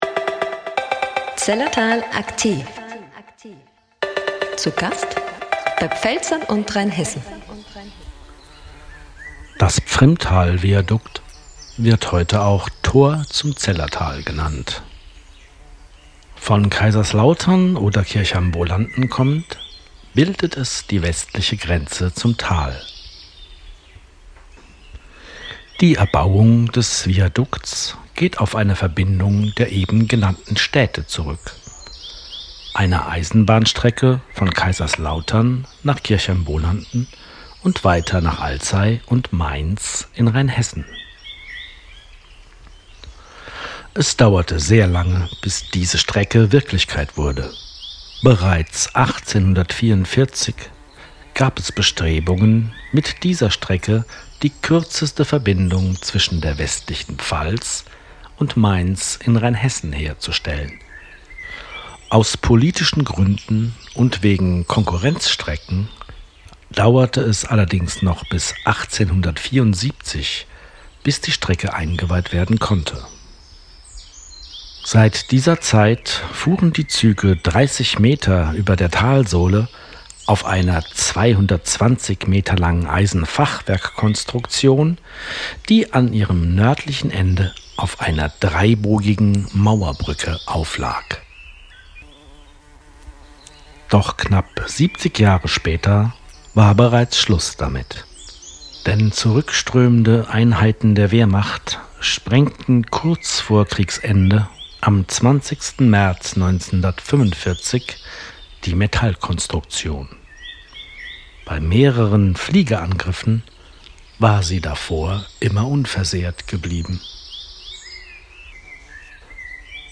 AUDIO-GUIDES